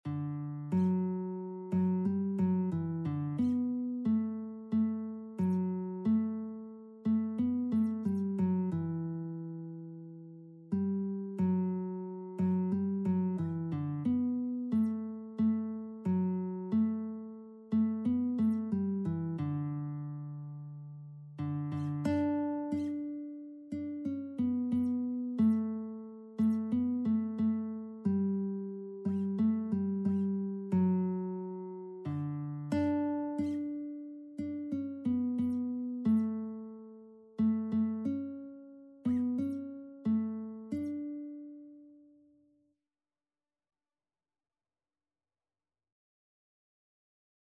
Christian Christian Lead Sheets Sheet Music I Know Whom I Have Believed
D major (Sounding Pitch) (View more D major Music for Lead Sheets )
4/4 (View more 4/4 Music)
Classical (View more Classical Lead Sheets Music)